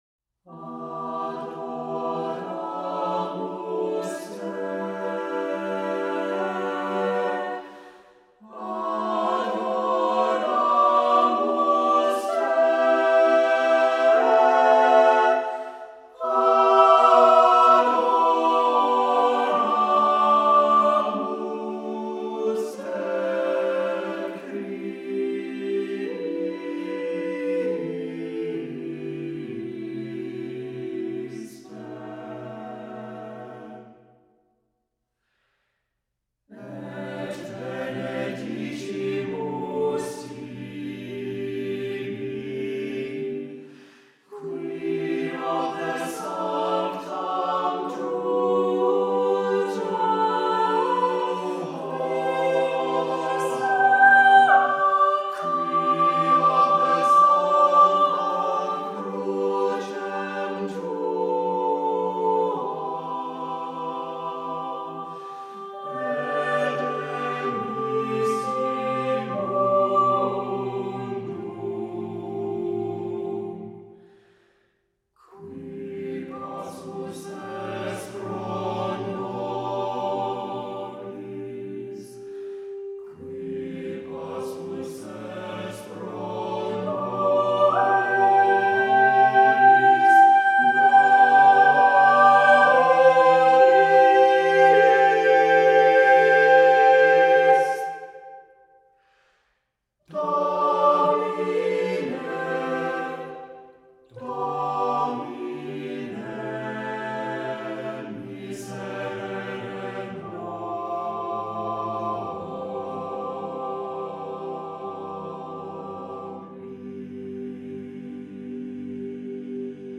Voicing: SATB,a cappella